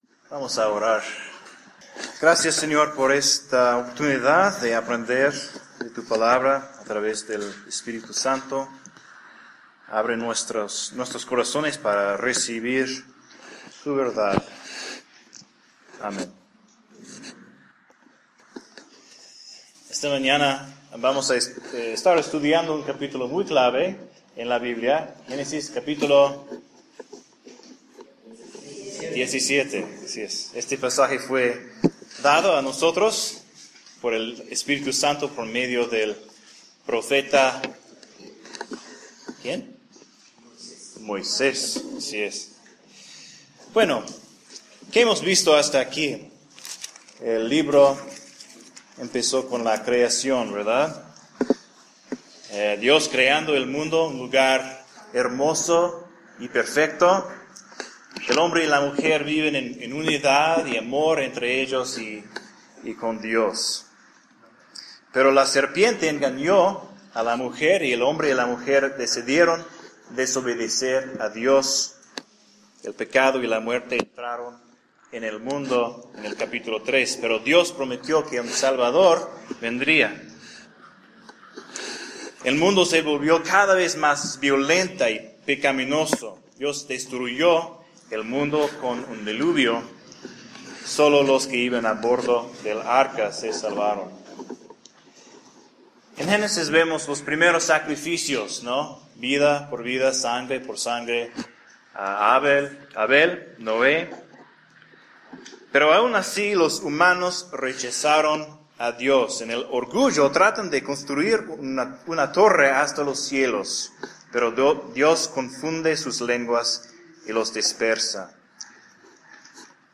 Un sermón de Génesis 17.
Génesis 17 (sermón)